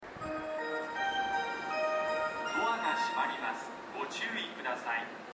スピーカーはＴＯＡ型が設置されており音質も高音質です。スピーカーの高さも低めですが 音量がやや小さめです。
発車メロディーフルコーラスです。